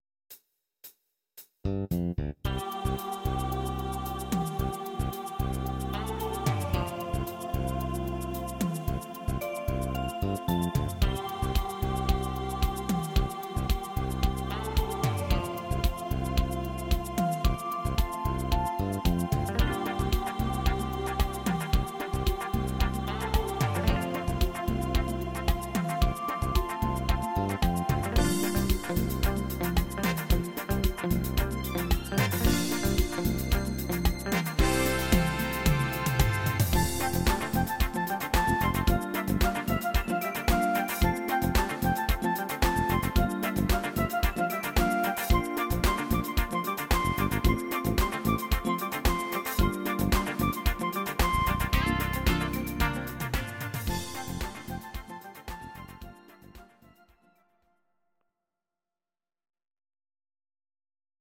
instr. Querflöte